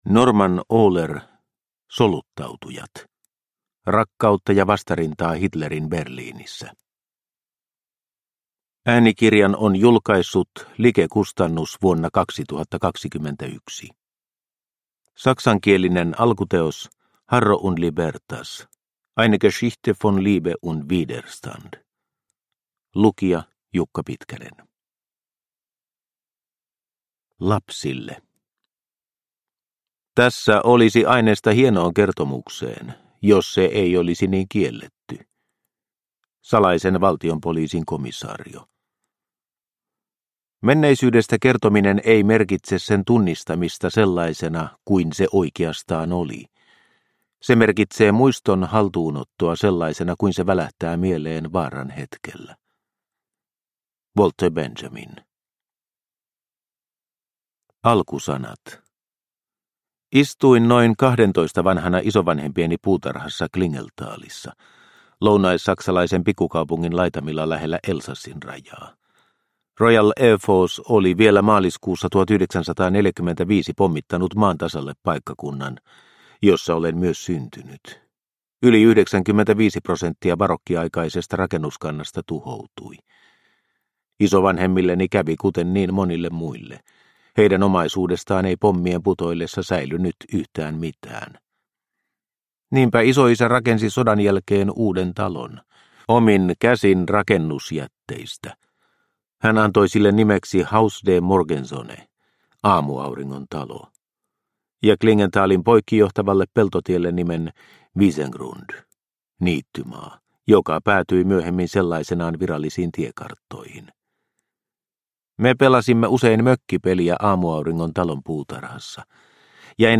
Soluttautujat – Ljudbok – Laddas ner